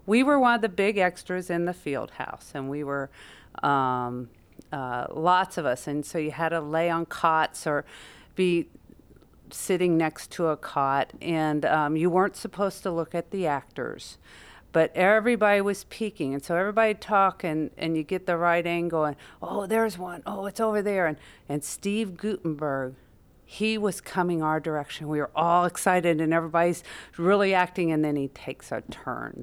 The interview was conducted at the Watkins Museum of History on June 28, 2012.